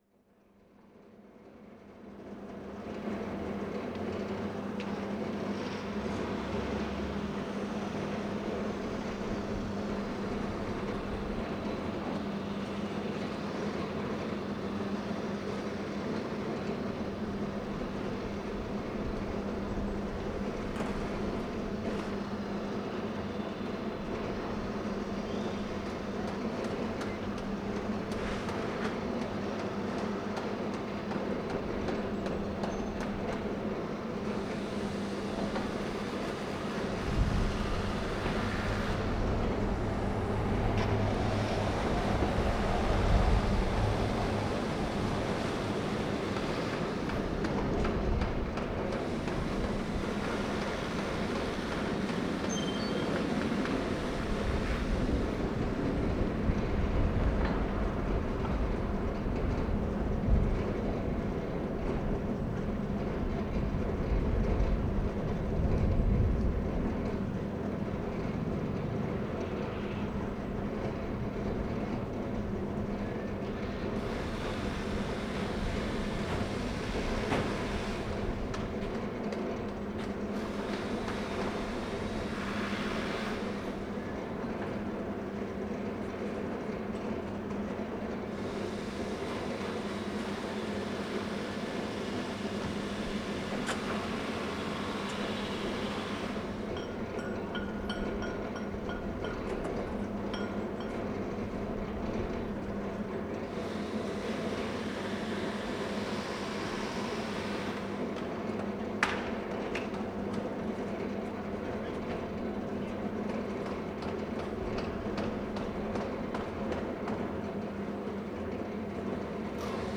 Recording from the recordist's balcony (the same with a concrete mixer and some cars) 3:06